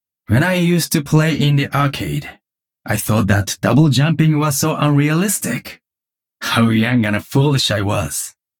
These voice lines are all from Heroes of the storm and I think they’re too good to not be in the game…plz jeff
GenjiBasePissed02.ogg